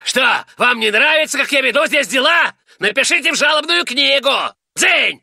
Дедушка Стэн в обиде: разве вам не по душе мое руководство здесь? - звук